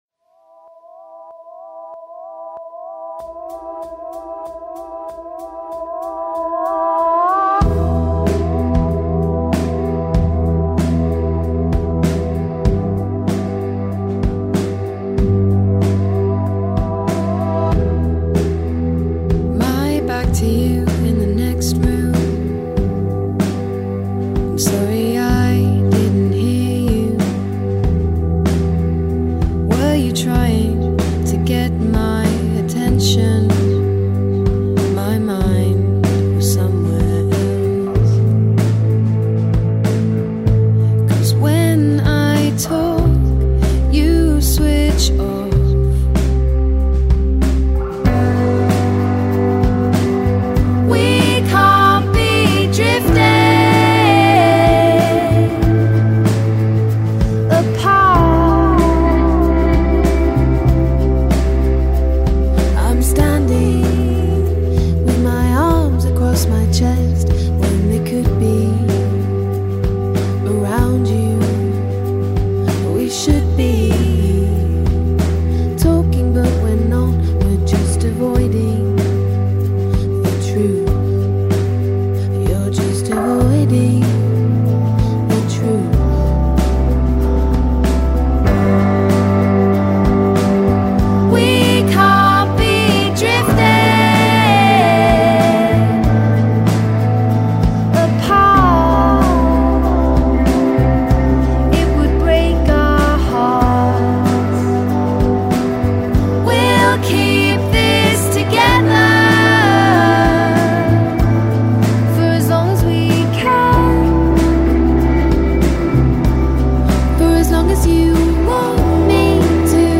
folk soaked vocal harmonies